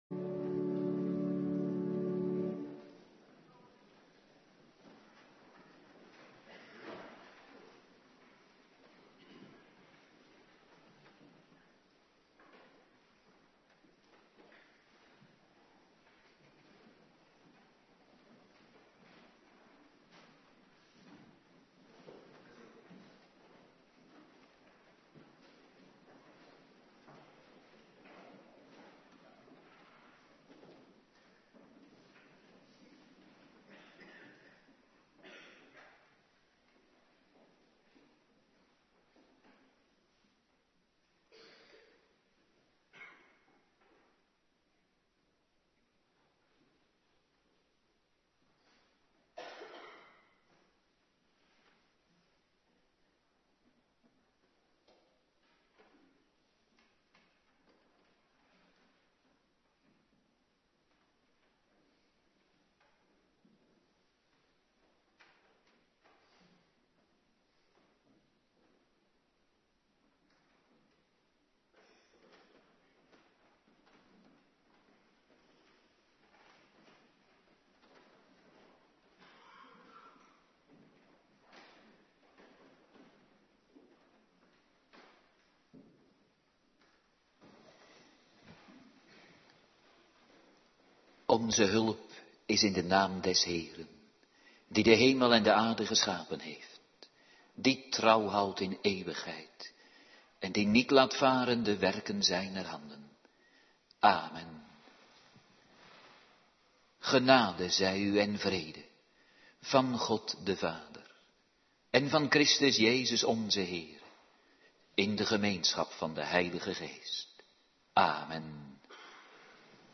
Preken (tekstversie) - Geschriften - De derde oorzaak van het huwelijk | Hervormd Waarder